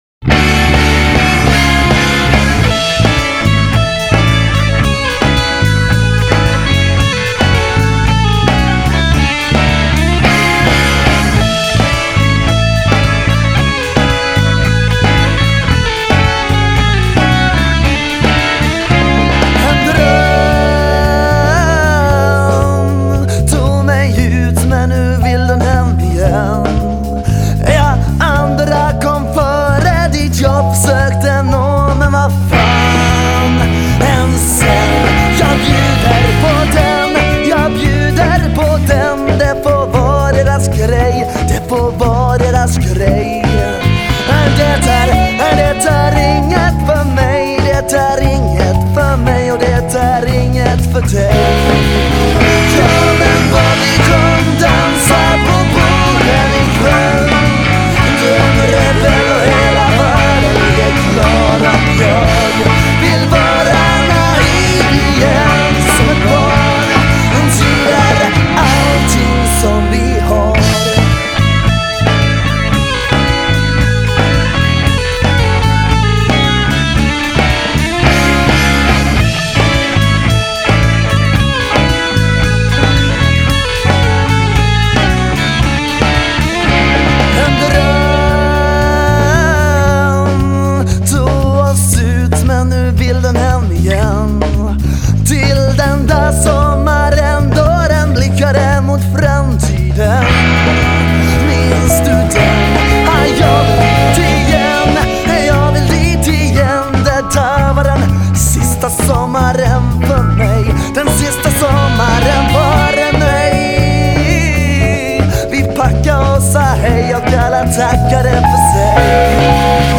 Inspelad i NLP-studios, Nybro, under en helg i Januari 2006